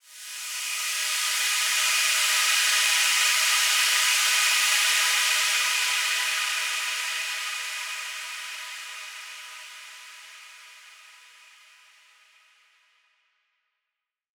SaS_HiFilterPad04-E.wav